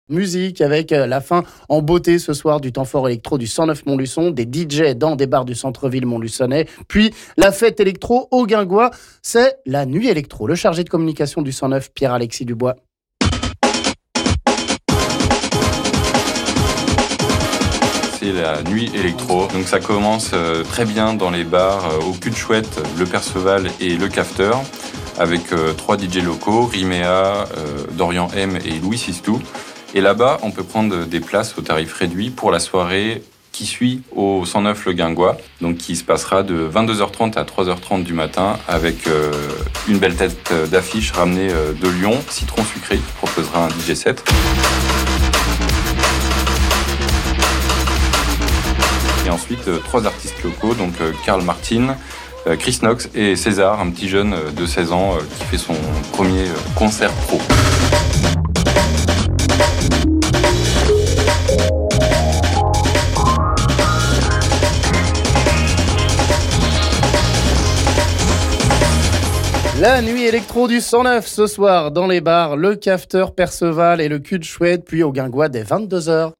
en musique...